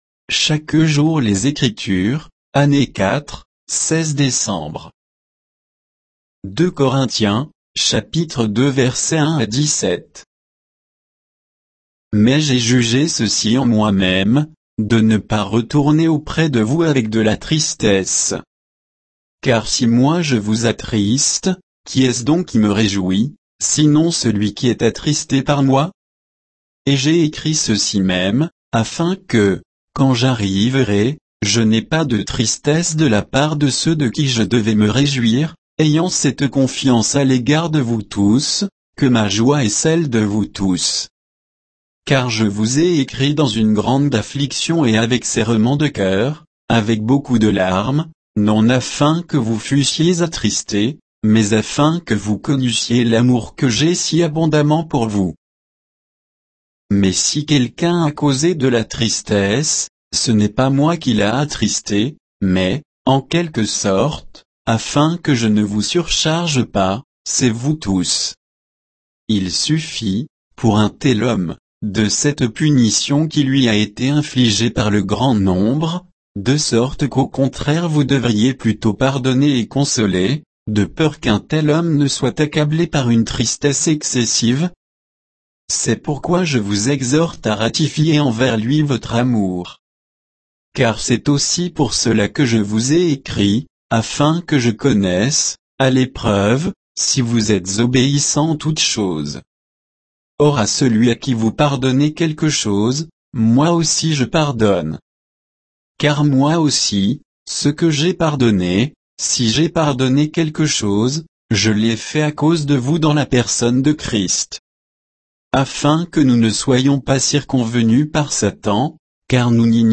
Méditation quoditienne de Chaque jour les Écritures sur 2 Corinthiens 2, 1 à 17